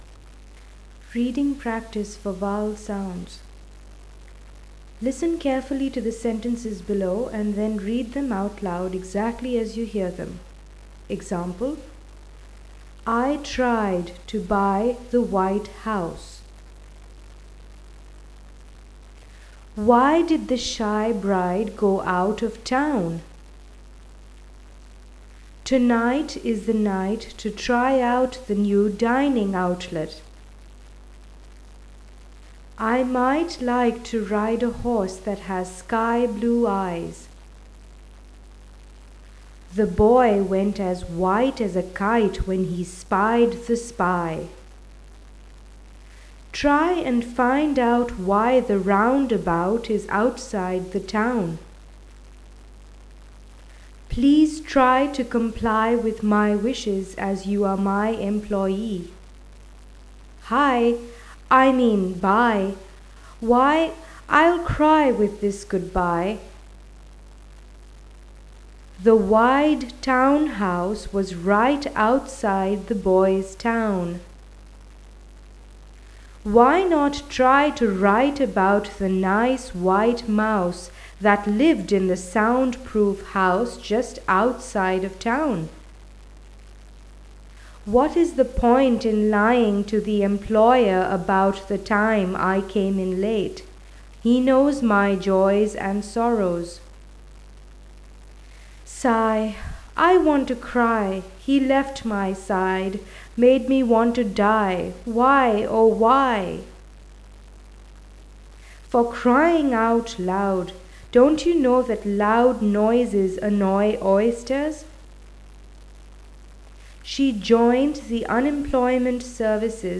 Vowels Reading Practice
vowels reading practice.wav